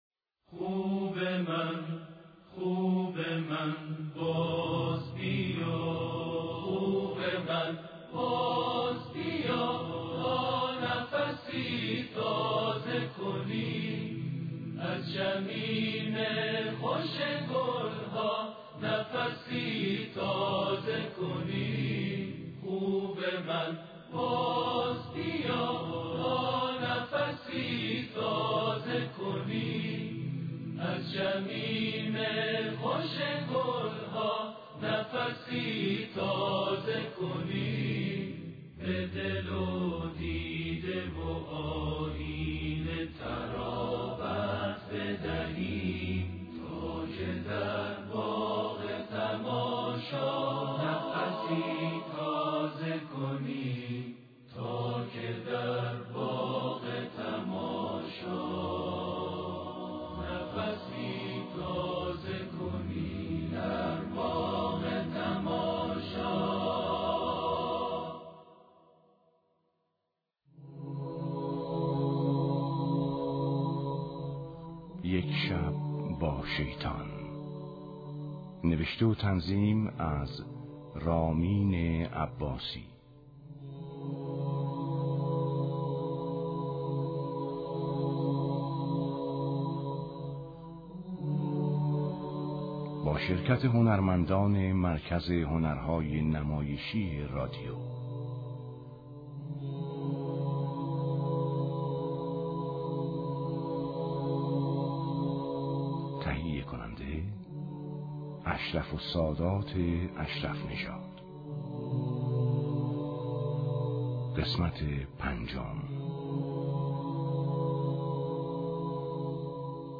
نمایش رادیویی - شبی با شیطان - بر اساس کتاب خاطرات مستر همفر جاسوس انگلیسی